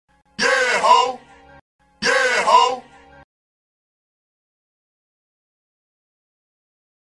( Offical ) Yeah Hoe Chant.mp3